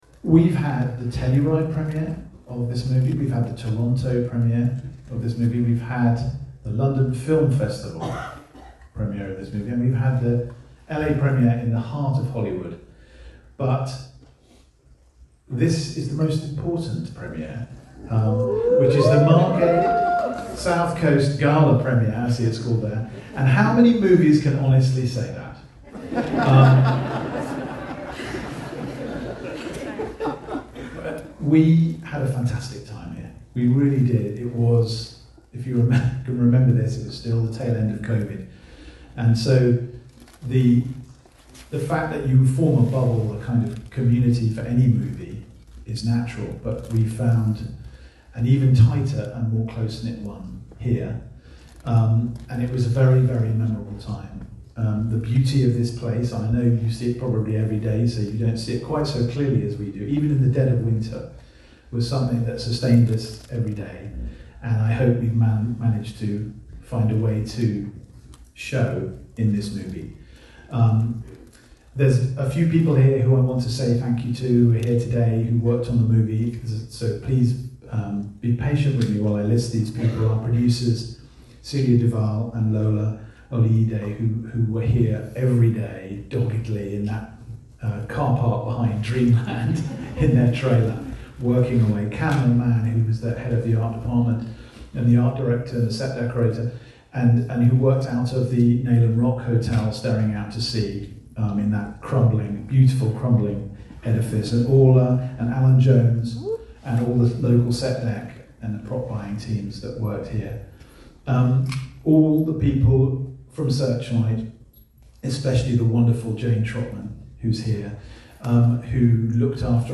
Listen: Director Sam Mendes thanks the people of Thanet at a special screening of Empire of Light - 08/01/2022